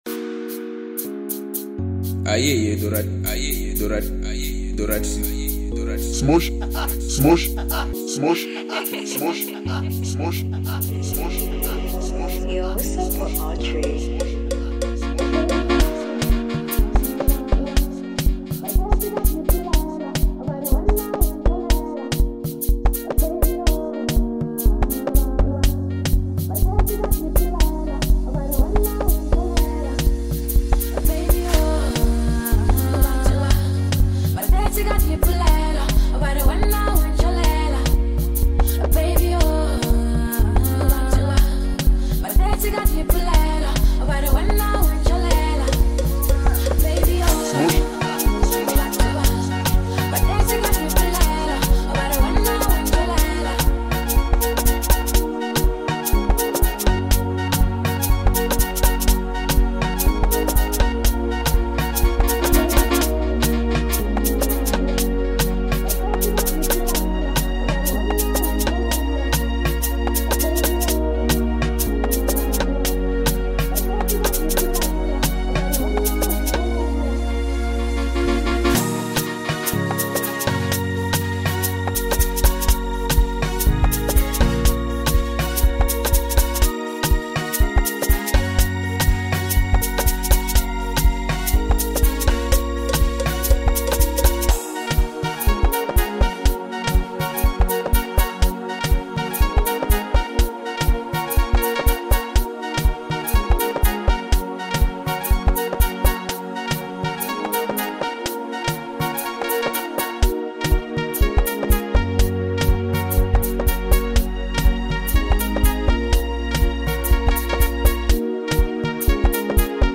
South African singer